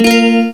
Index of /m8-backup/M8/Samples/Fairlight CMI/IIX/GUITARS